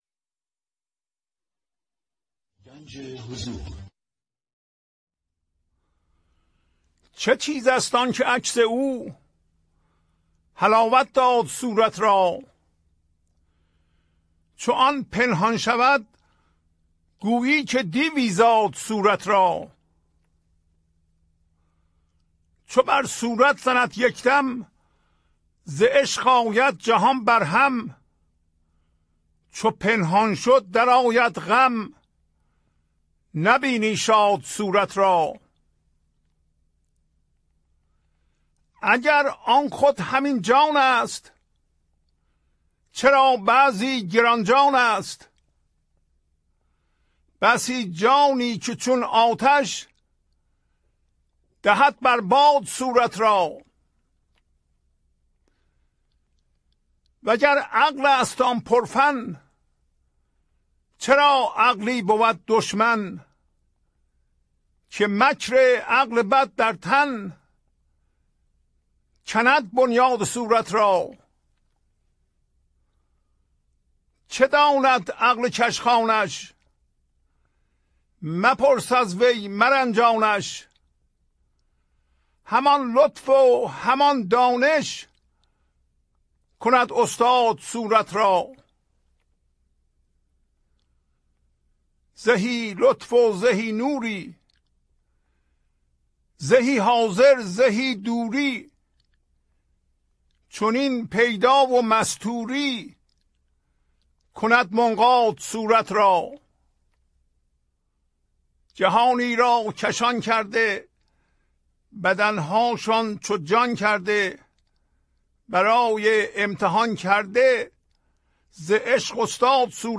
خوانش تمام ابیات این برنامه - فایل صوتی
1030-Poems-Voice.mp3